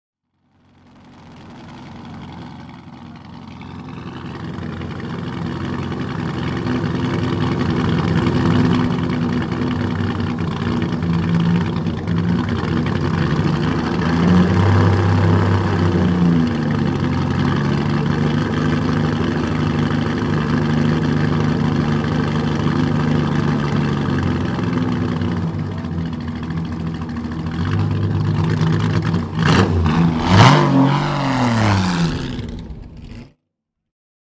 Ferrari 375 MM (1953)
Ferrari_375_MM.mp3